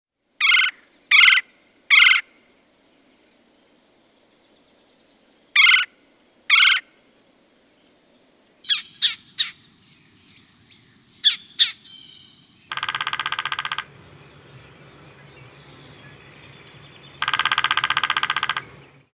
Red-bellied Woodpecker
redbelliedwoodpecker.mp3